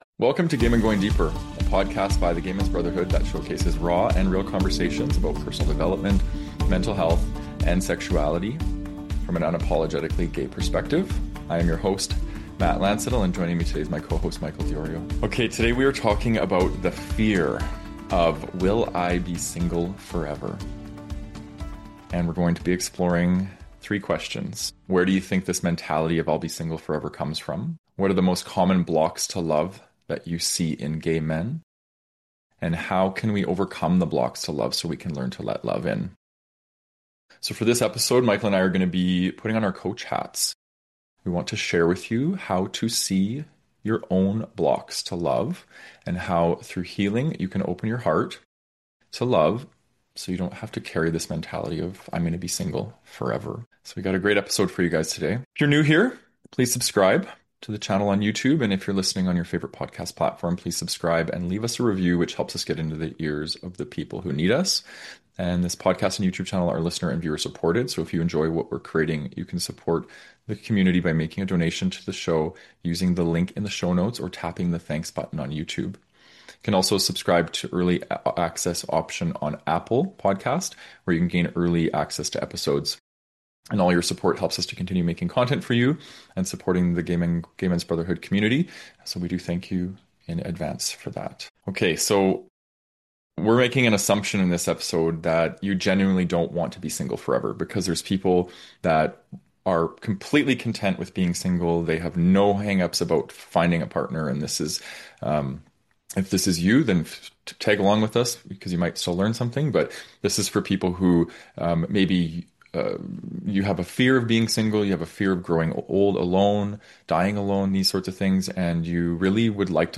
This conversation isn’t about forcing positivity or dating tactics.